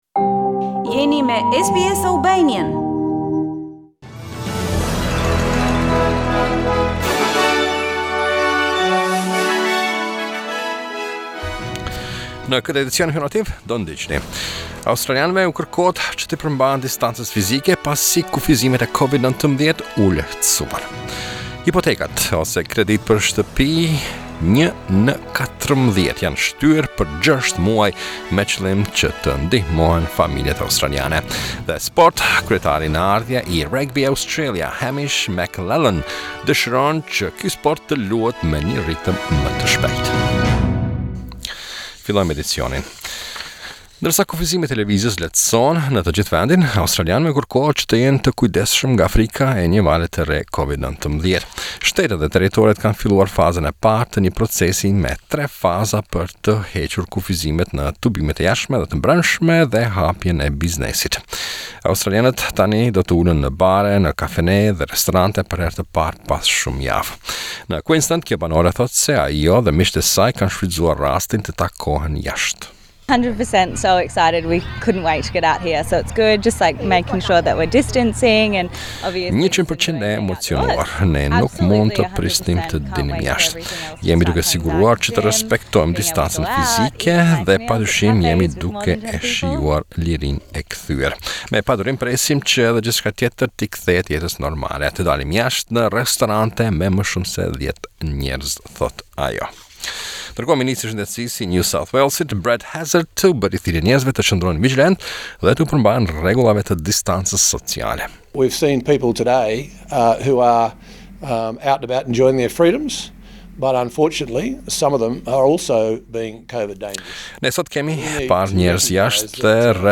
SBS News Bulletin - 16 May 2020